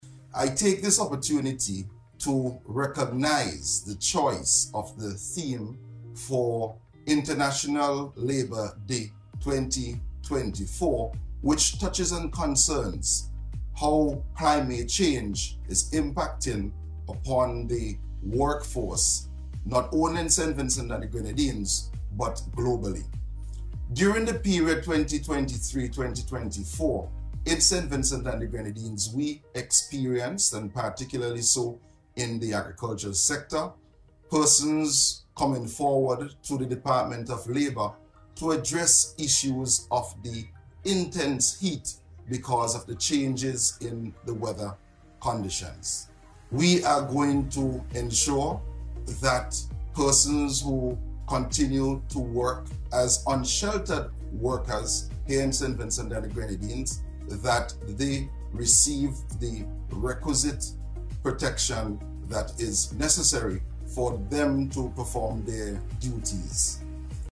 In an address to mark the day, Minister Caesar spoke about the significance of this year’s theme.